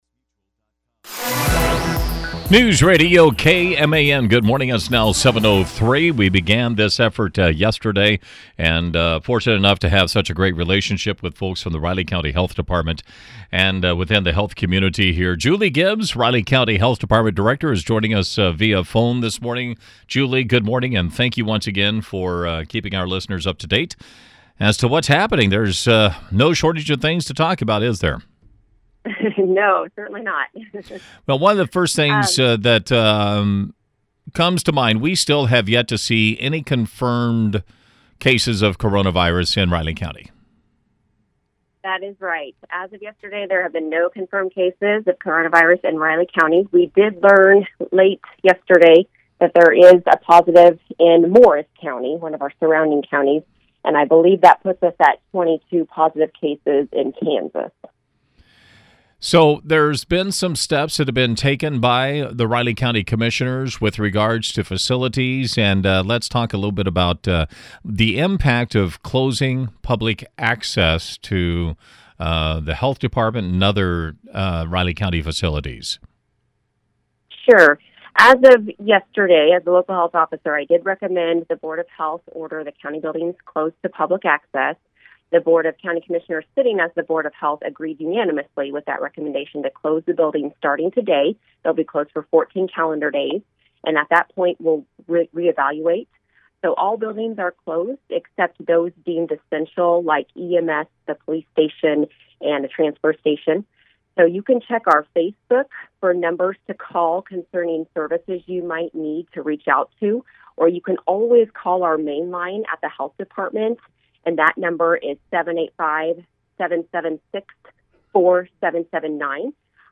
Riley County Health Department Director Julie Gibbs joined us Thursday for a daily update on the coronavirus response locally.